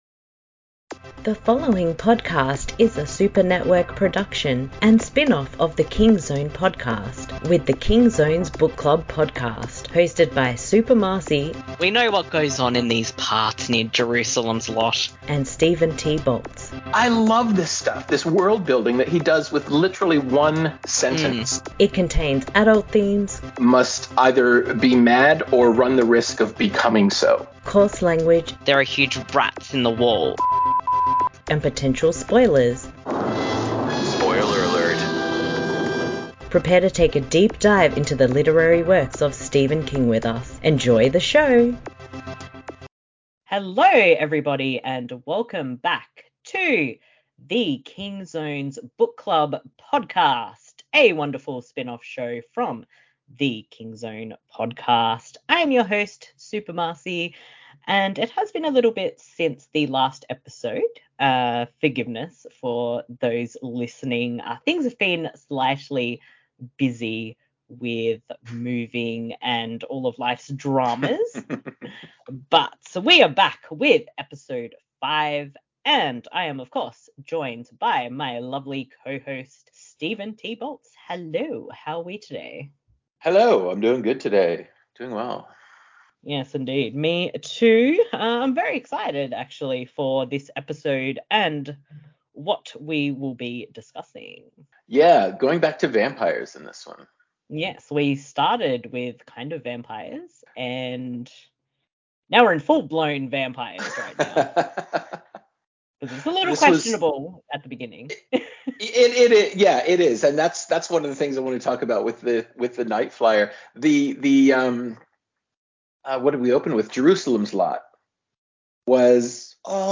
The King Zone’s Book Club Podcast Episode 05 – More Vampire Short Stories Discussion with The Night Flier and Popsy